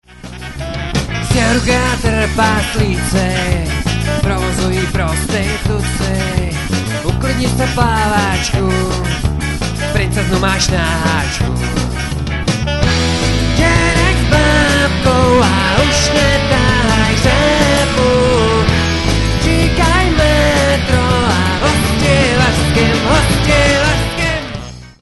Nahráno na jaře 2005 v Říčanech.